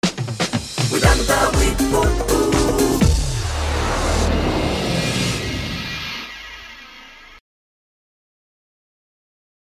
Jingle amb la freqüència de l'emissora.